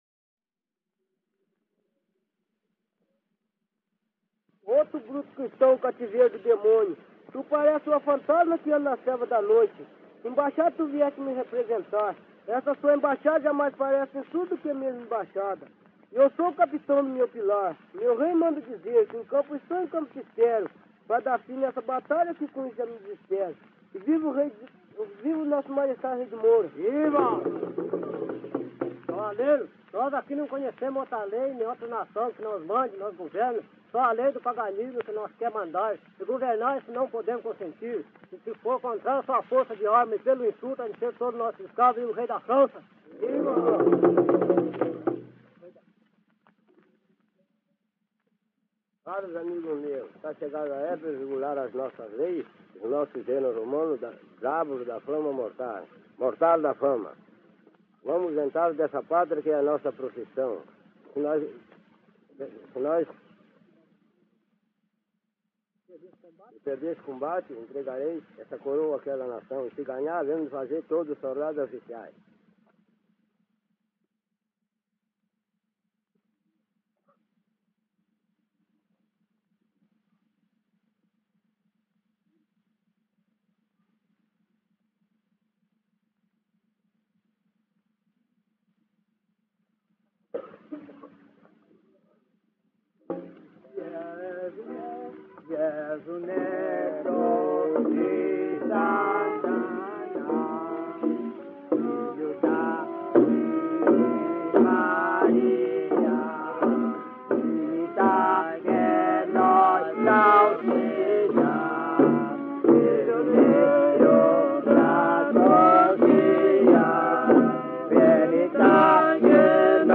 Fragmento de embaixada de Congada (2)